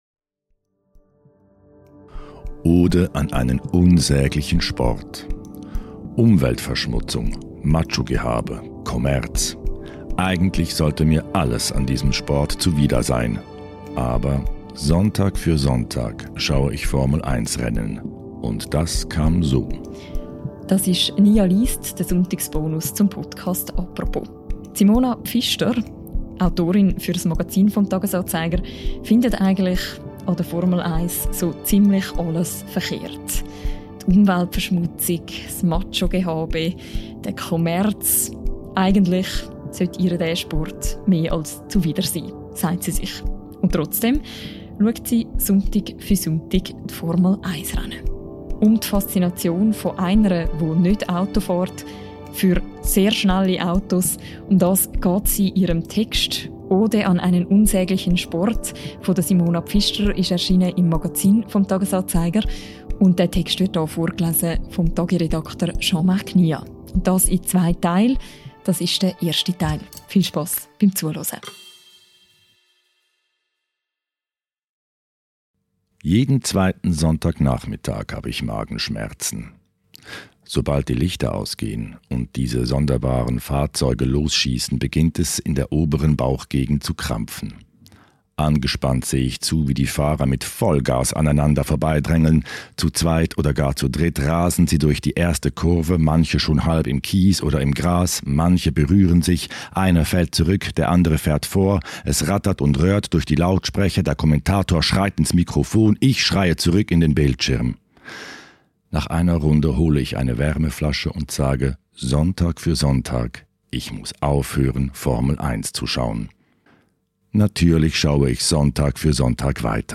Ihr Text wird vorgelesen